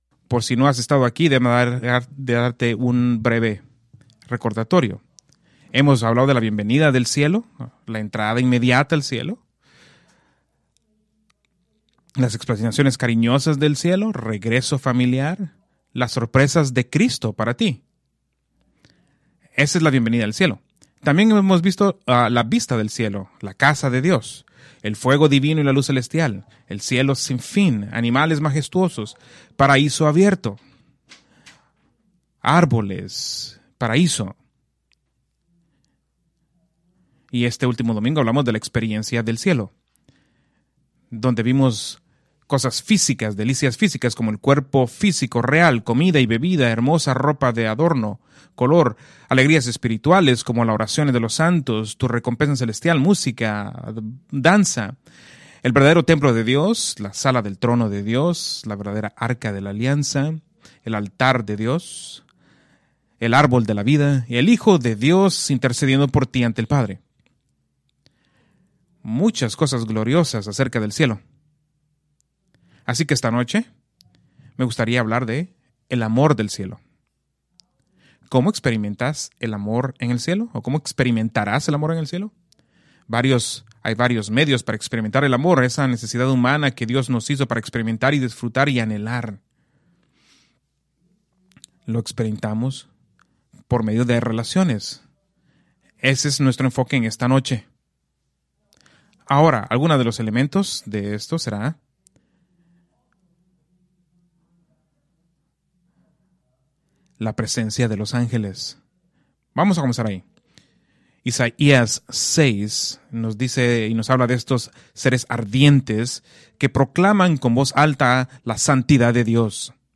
Preached December 24, 2024 from Escrituras seleccionadas